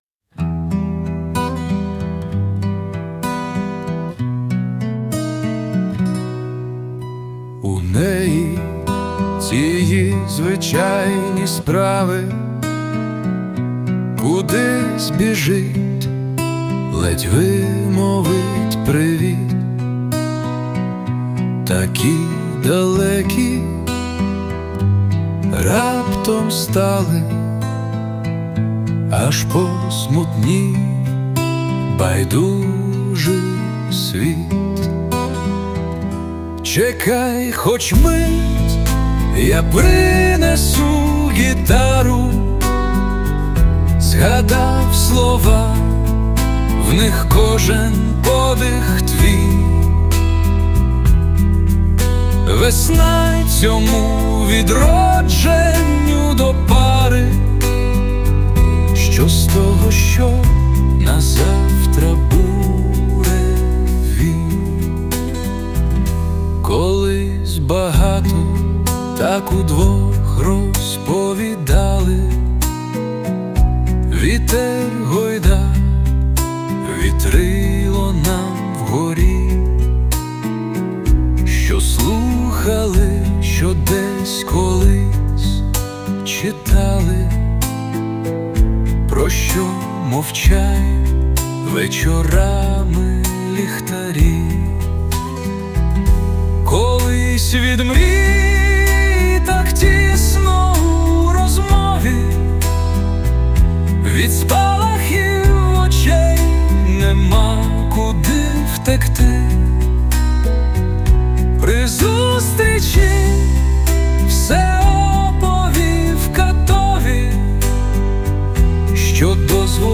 Кавер з допомогою SUNO
СТИЛЬОВІ ЖАНРИ: Ліричний